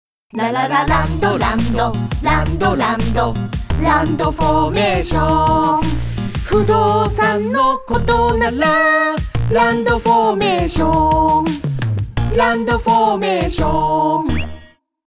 バスの車内放送広告、始めました！